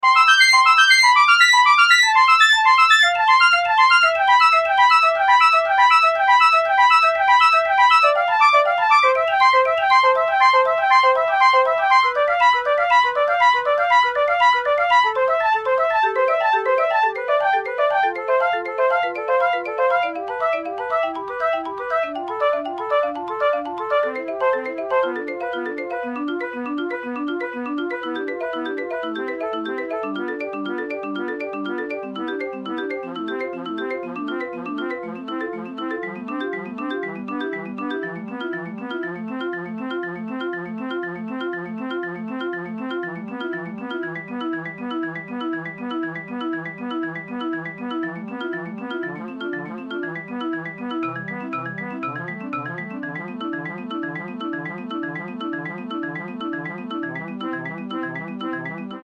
Portugal – Marimba /4 oitavas / track tempo: 8 x França – Trompete /3 oitavas / track tempo: 8 x